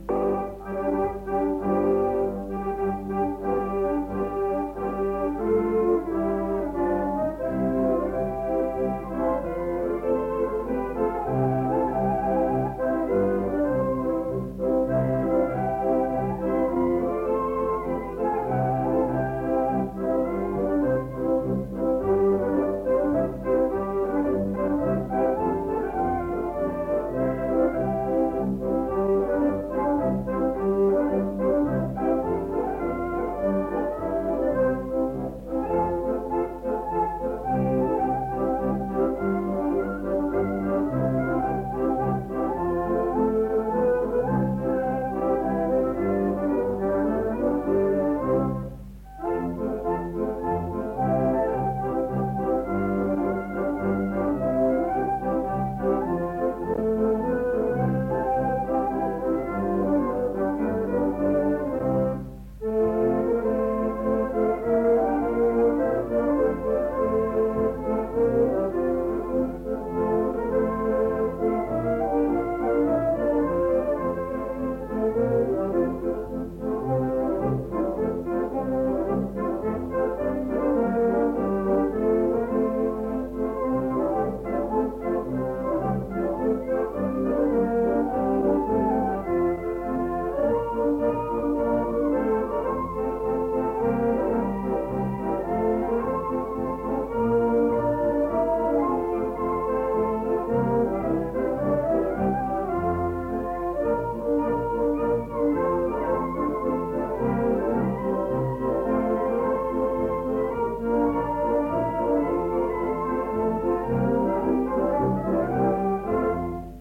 Instrumentalny 13 (Polonez zespołowy) – Żeńska Kapela Ludowa Zagłębianki
Nagranie archiwalne
Instrumentalny-13-Polonez-zespolowy.mp3